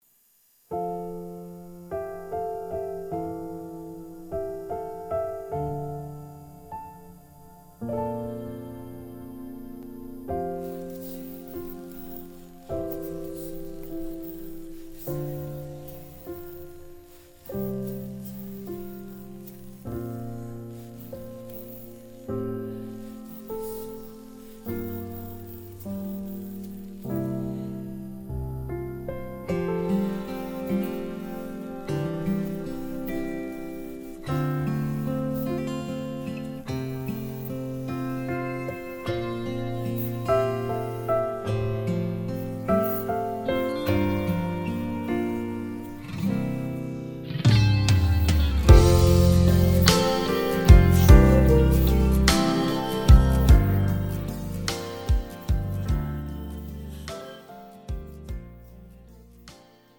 미리듣기
음정 원키
장르 가요 구분